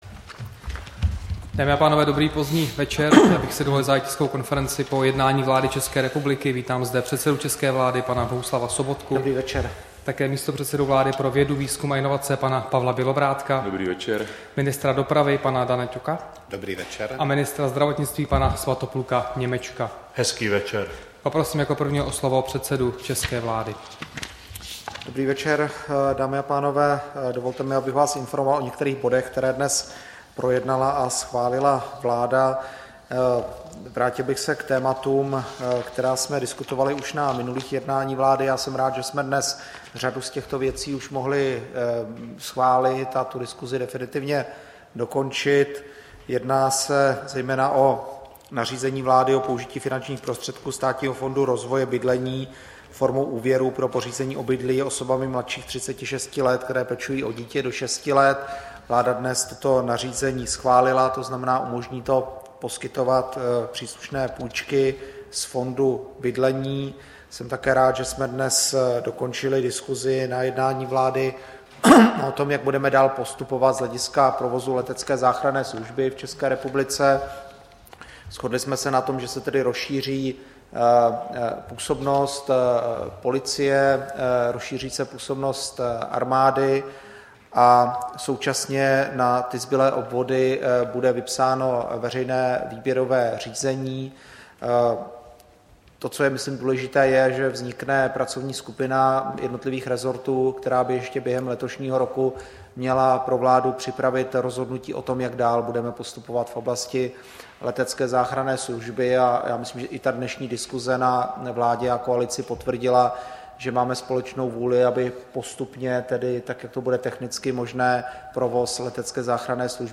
Tisková konference po jednání vlády, 9. března 2016